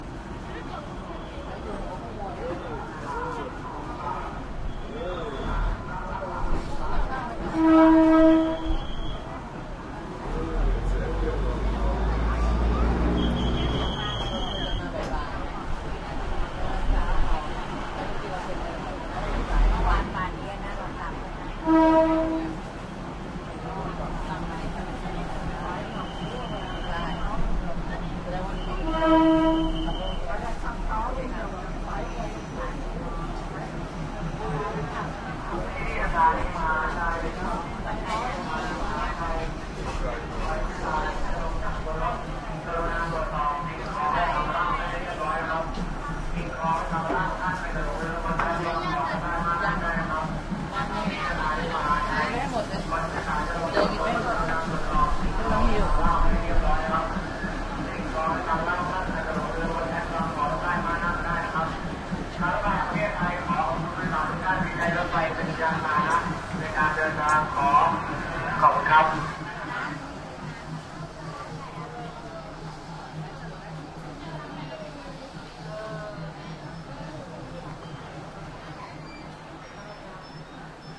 泰国 " 泰国客运通勤列车上的露天泰式喋喋不休的瓦拉开始停止旅行的各种，面对着门框。
描述：泰国客运通勤列车露天上车泰国喋喋不休的瓦拉开始停止旅行各种，面对门的平衡轨道运动和响亮的发动机
标签： 喋喋不休 露天 泰国 火车 通勤 船上 乘客 沃拉
声道立体声